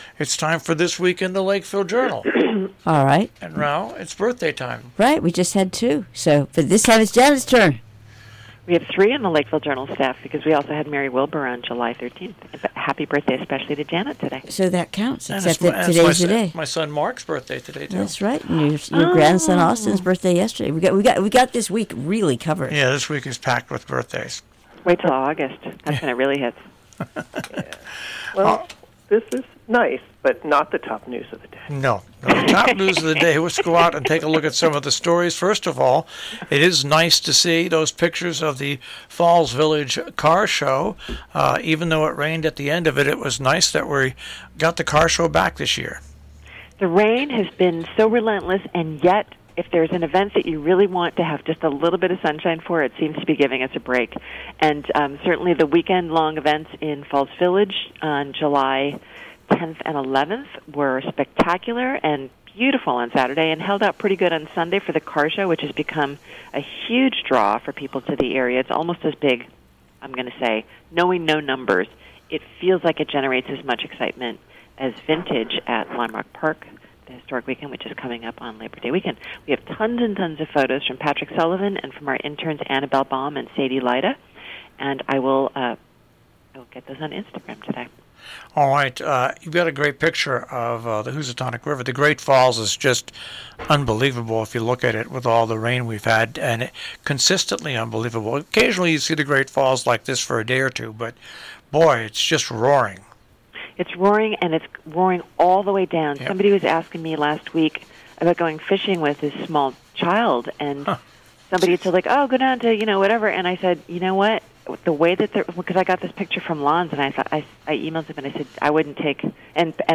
Sorry a computer error only recorded the first 10 minutes of the show this week!!!!!